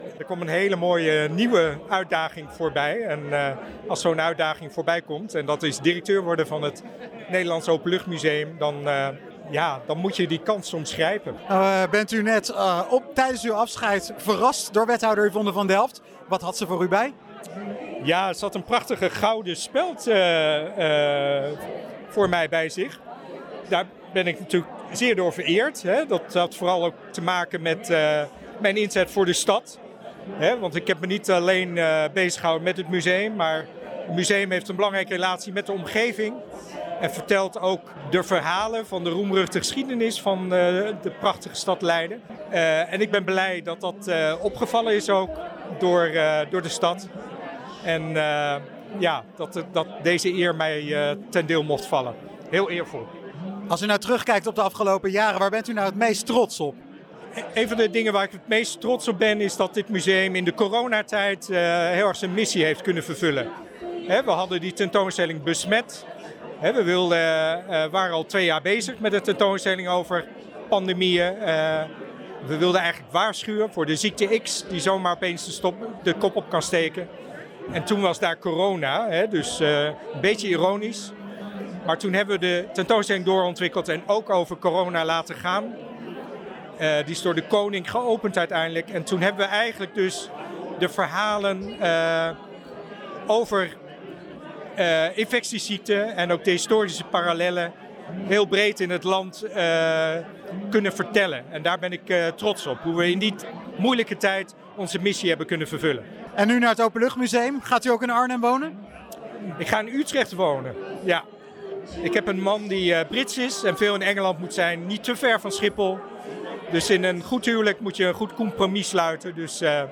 in gesprek met verslaggever